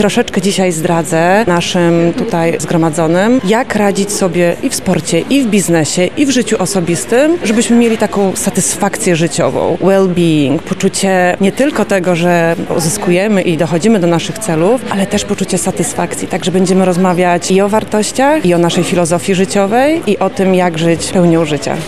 Dziś (9 marca) z okazji Dnia Kobiet na Politechnice Lubelskiej odbyła się druga edycja wydarzenia pt. „Future Women – Kobiety Kształtujące Jutro”. Tegoroczne spotkanie było skupione wokół sportu.